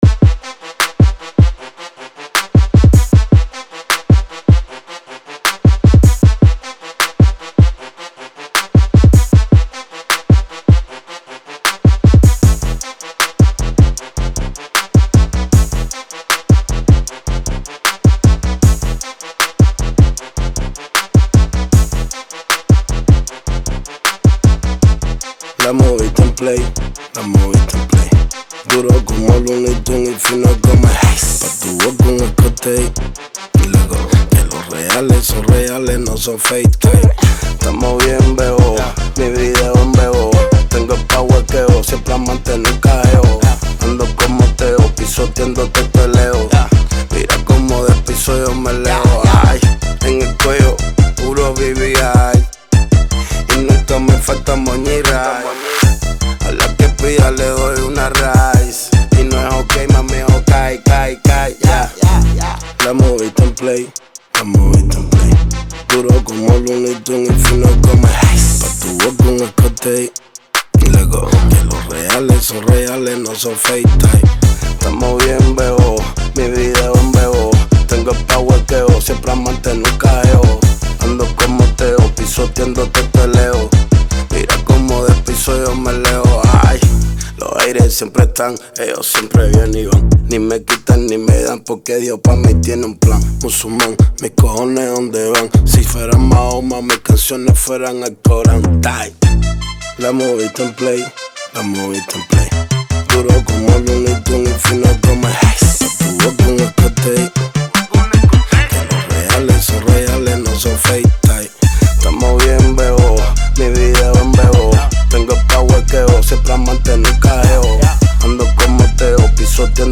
Genre: Latin Trap.